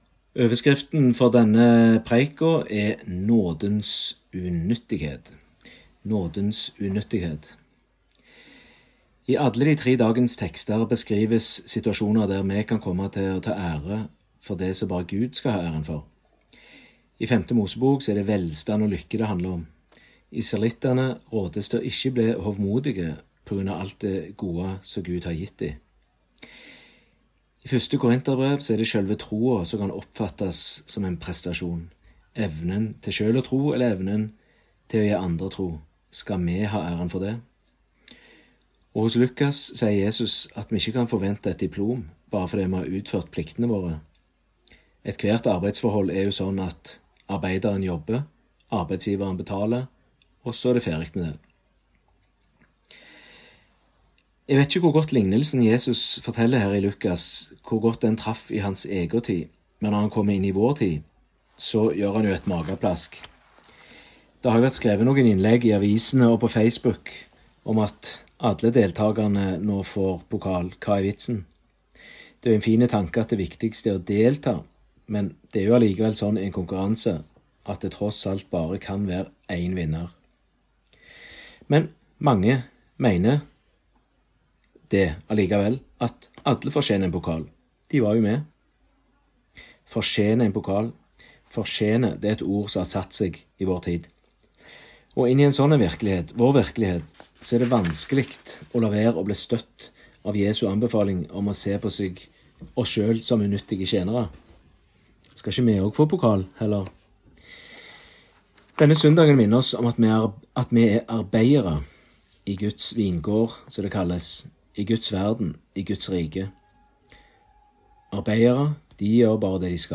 Utdrag fra talen